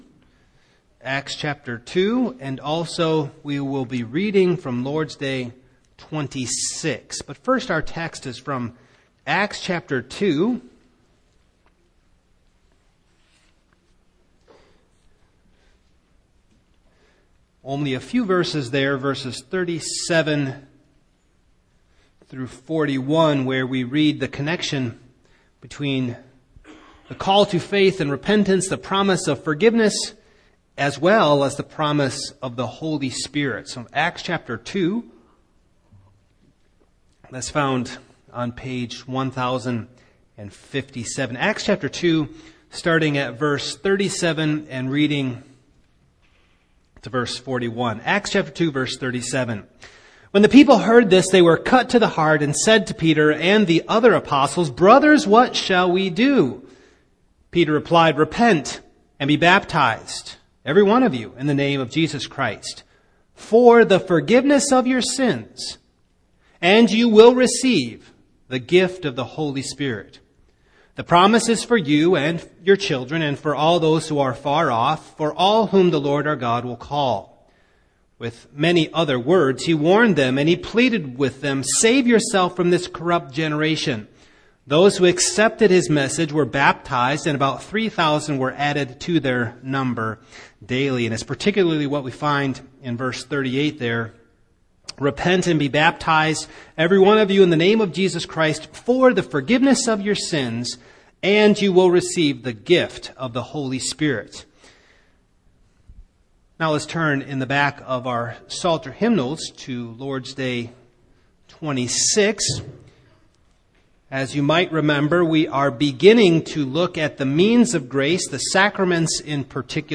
Passage: Acts 2:37-41 Service Type: Evening